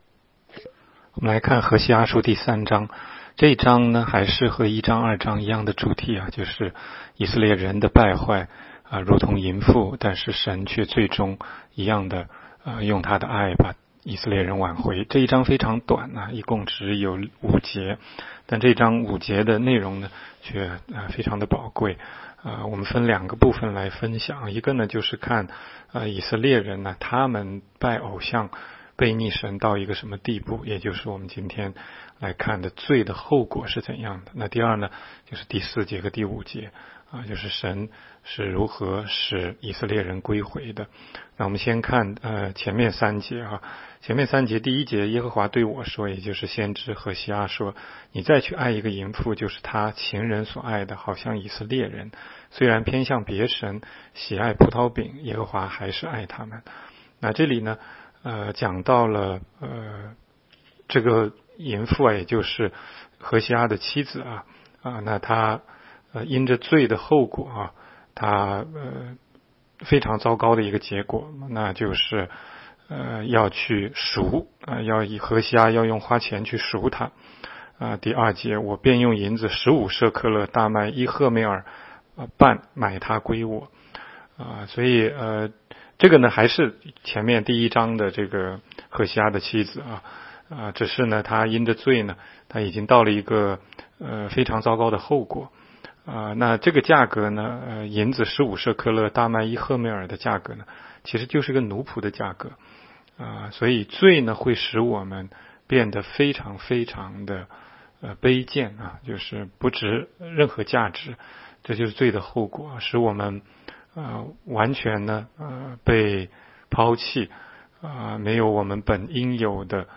16街讲道录音 - 每日读经 -《何西阿书》3章